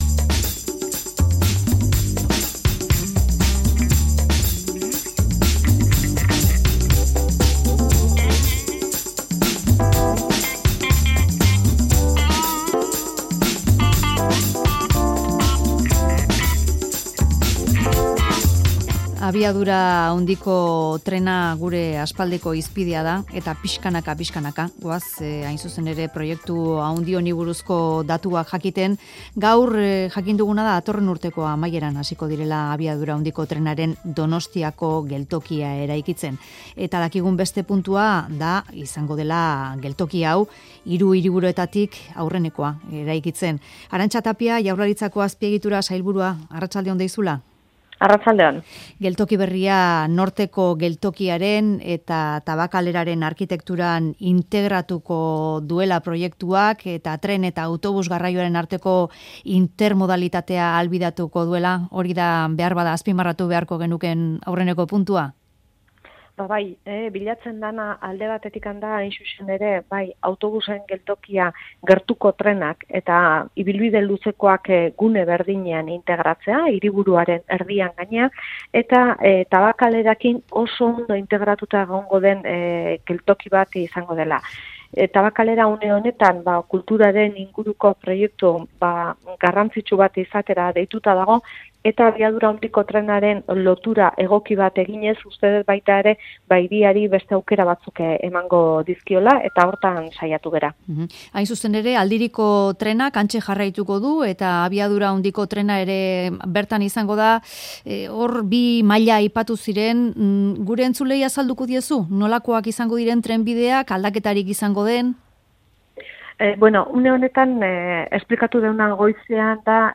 Audioa: 2019aren bukaeran hasiko dituzte Donostiako AHTaren geltokia eraikitzeko lanak, eta Tabakalera eta Norteko Geltokiarekin batera integratuko da. Arantza Tapia Azpiegitura sailburuak eman ditu azalpenak.